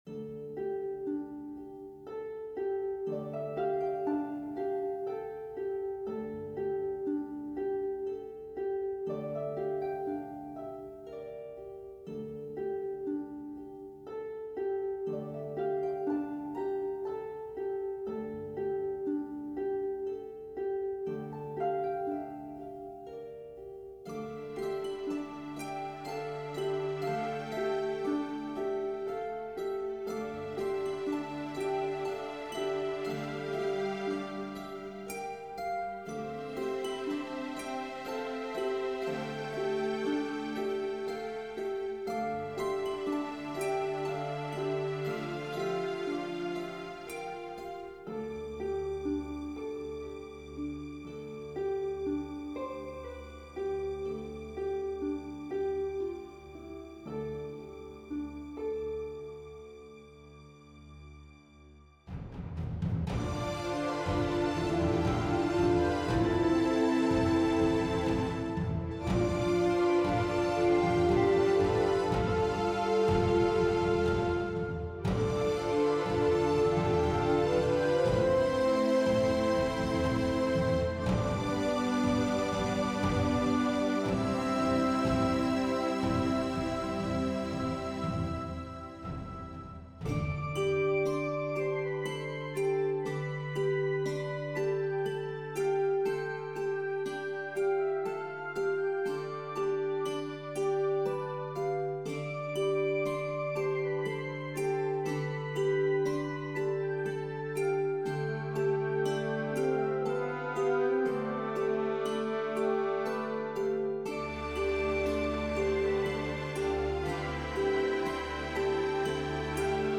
Fantasy Music - In Search of the Philosophers Stone
Hello All, Just a short orchestral piece that is sadly not really made to loop but, would probably be a pretty cool title track.